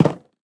Minecraft Version Minecraft Version snapshot Latest Release | Latest Snapshot snapshot / assets / minecraft / sounds / block / cherrywood_fence_gate / toggle2.ogg Compare With Compare With Latest Release | Latest Snapshot
toggle2.ogg